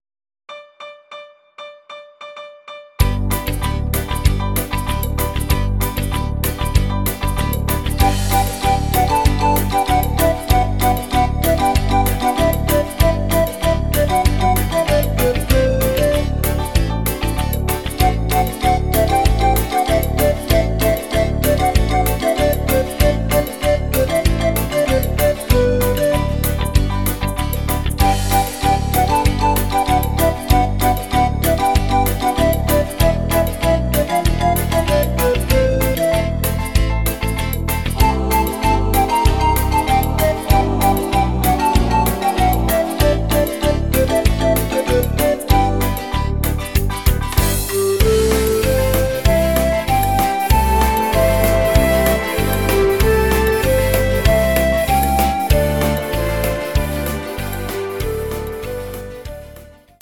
Audio Recordings based on Midi-files
Oldies, Musical/Film/TV, German